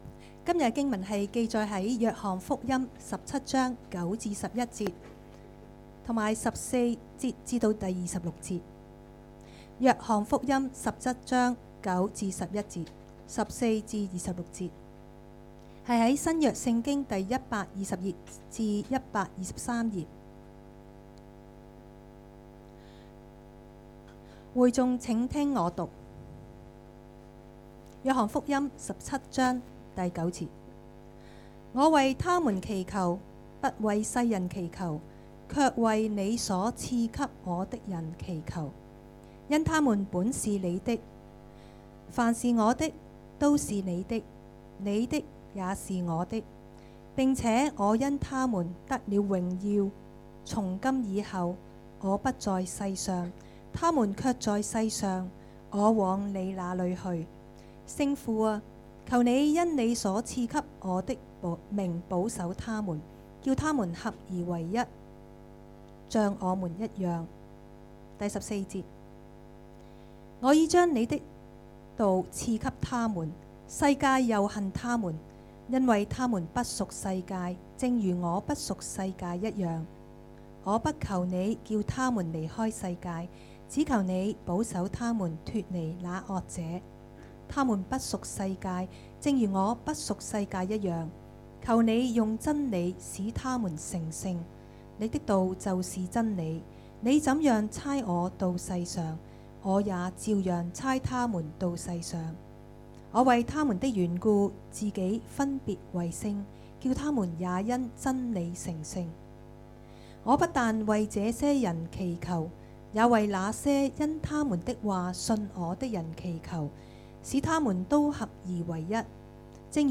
2019年10月6日崇拜
崇拜講道